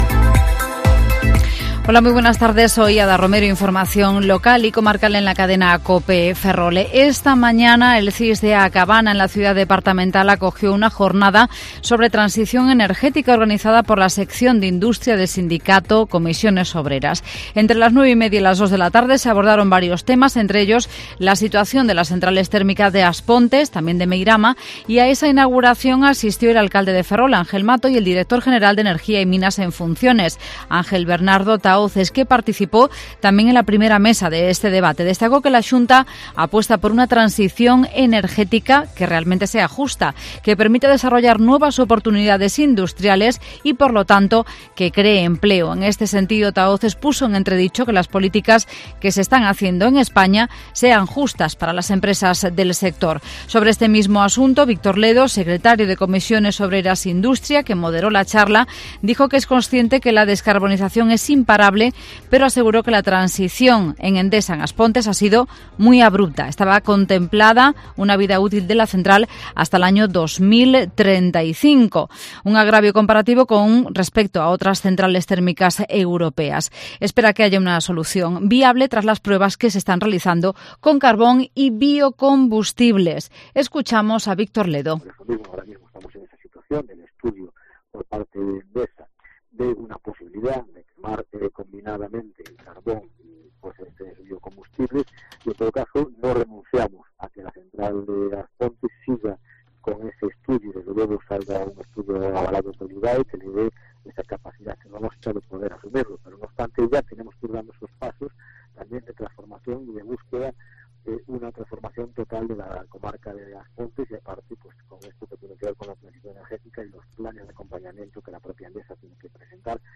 Informativo Mediodía COPE Ferrol 15/07/2020 ( de 14,20 a 14,30)